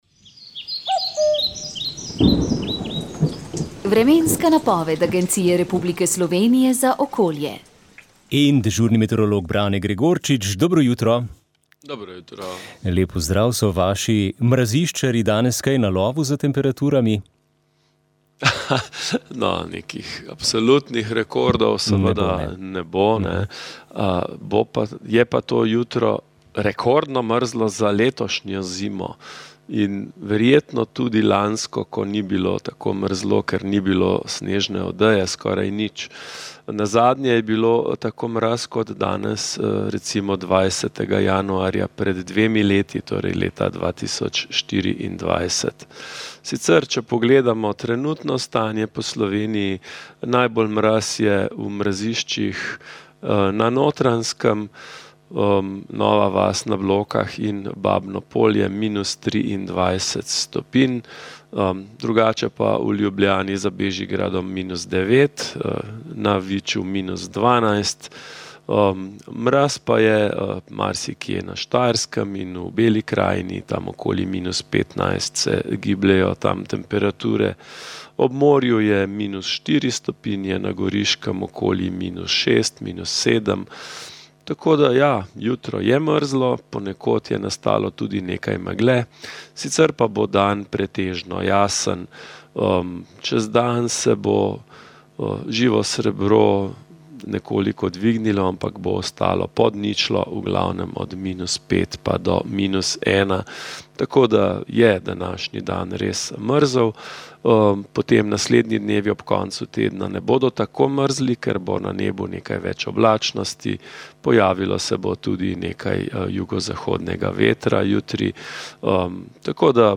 Priporočamo | Aktualno Komentar tedna VEČ ...